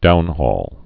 (dounhôl)